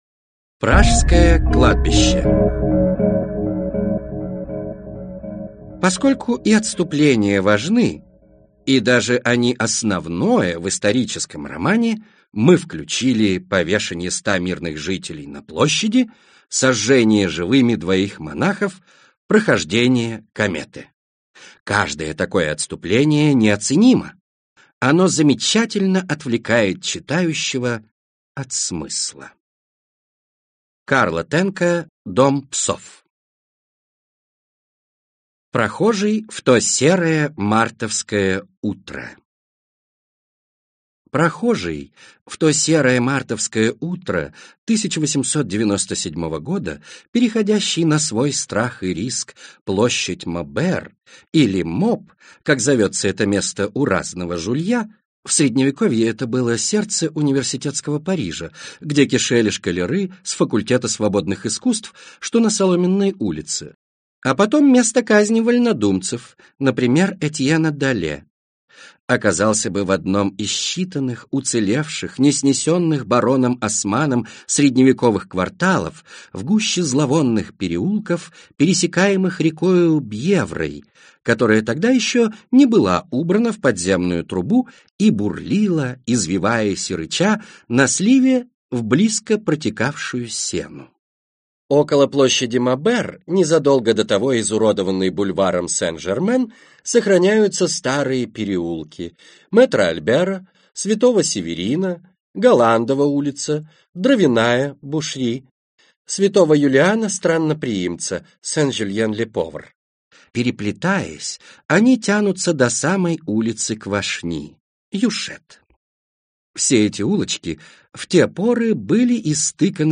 Аудиокнига Пражское кладбище - купить, скачать и слушать онлайн | КнигоПоиск